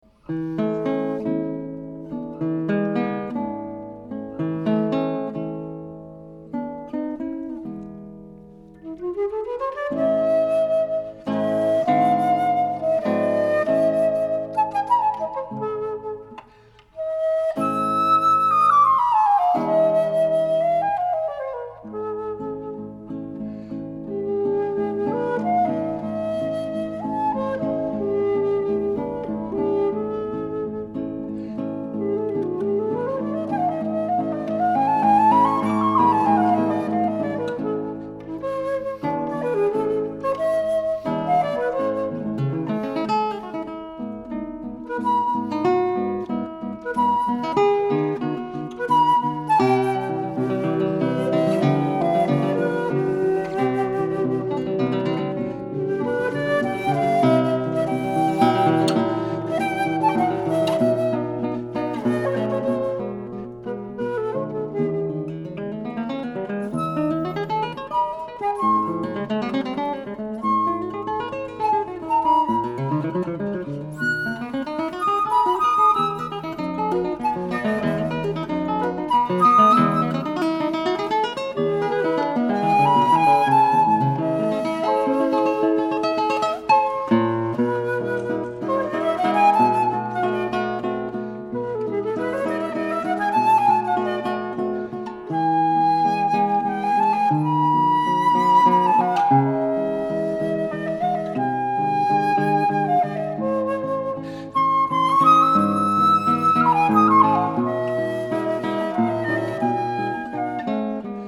“Lied”